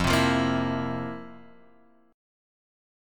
F Major 7th Suspended 4th
FM7sus4 chord {1 3 3 3 x 0} chord